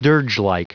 Prononciation du mot dirgelike en anglais (fichier audio)
Prononciation du mot : dirgelike